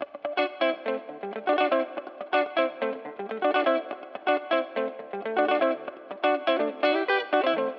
08 Pickin Guitar PT 1-4.wav